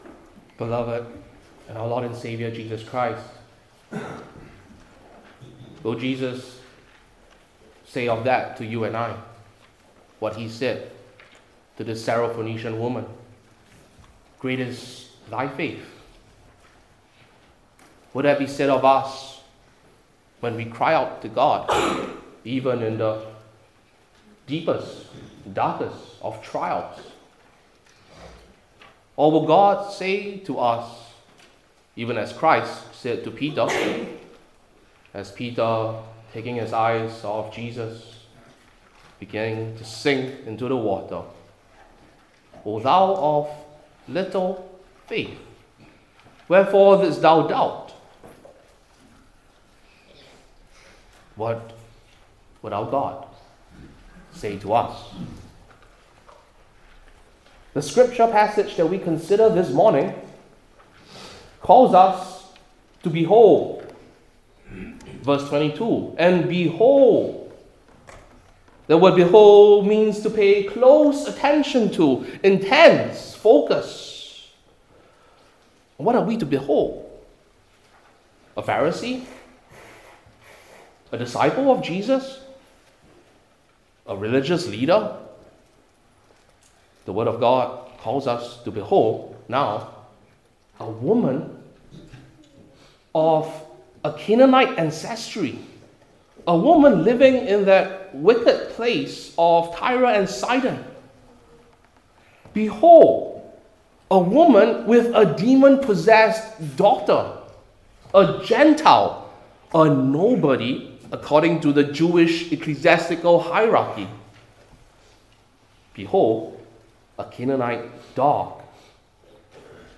New Testament Individual Sermons I. The Woman’s Plea II.